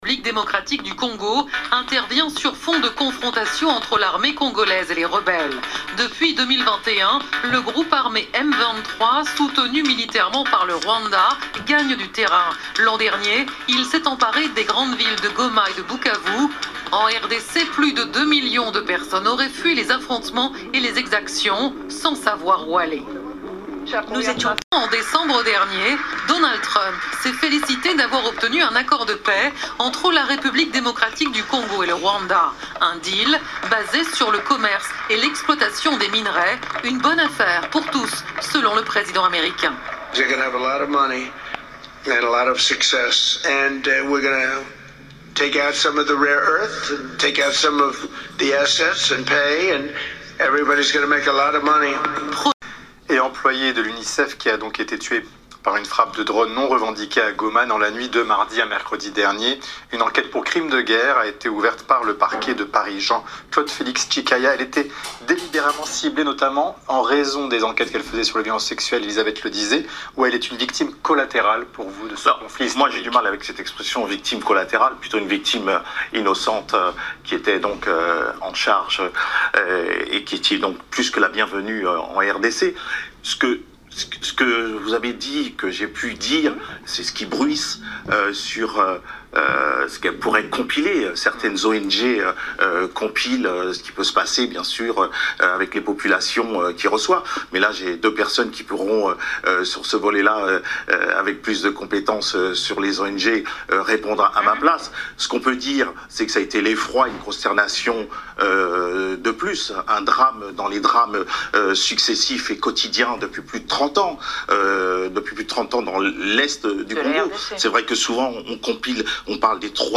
Je vais le reprendre, de manière plus ou moins originale, en une suite de dialogues entre Espérance et d' autres interlocuteurs dans une pièce de théâtre en 7 actes.